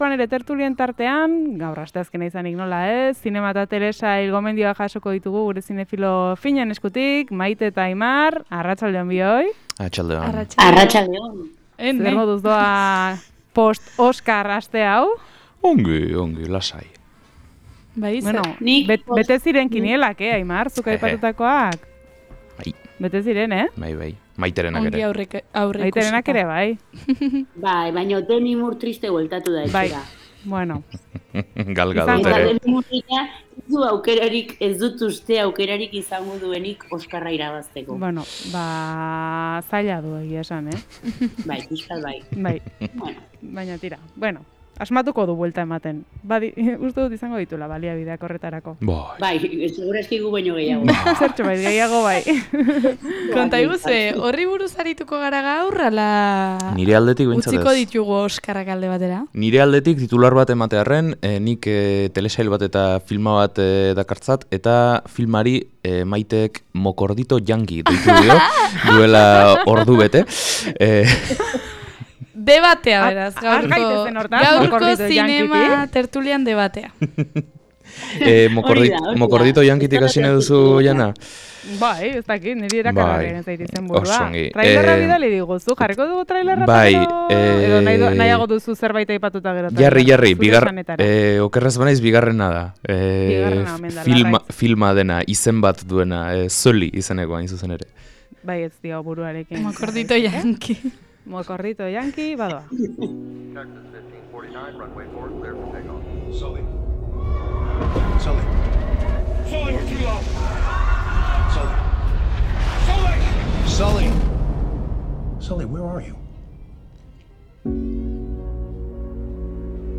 Zinema tertulia · 03.05